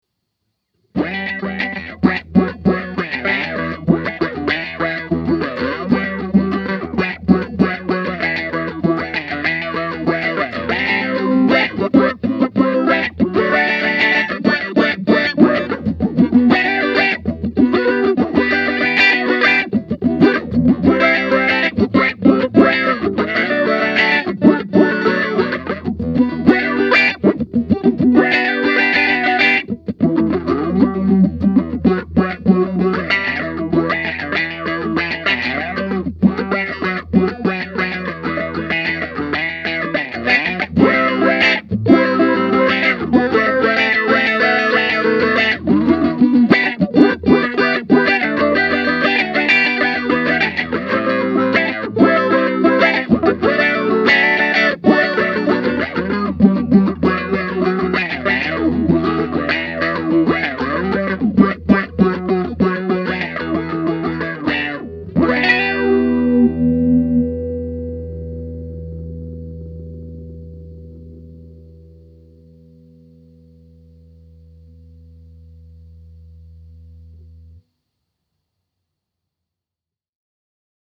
The following clips were all played with my Fender American Deluxe Strat into my trusty Roland Cube 60, set to a clean “Blackface” setting.
Finally, I set the pedal to extremes. I was after the most raunchy tone that the pedal can  produce without going over the top (which it can), and this is the result: